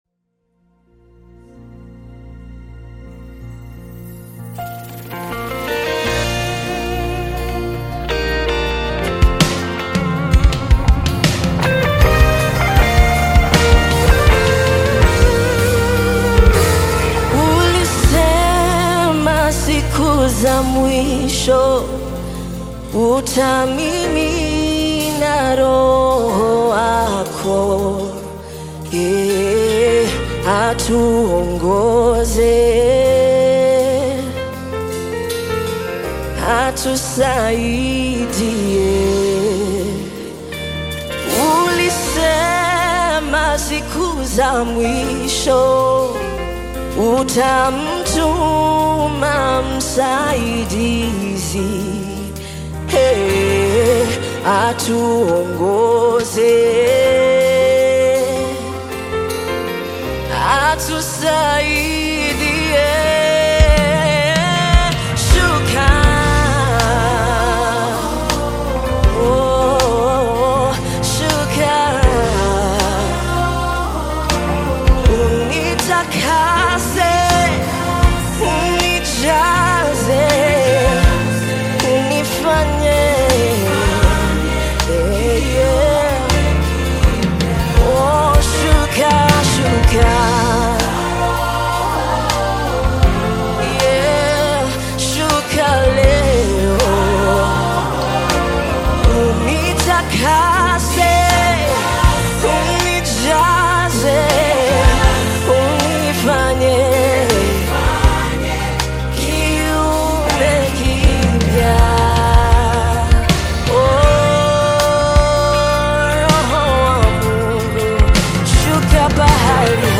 Gospel music track
Kenyan gospel artist, singer, and songwriter
Gospel song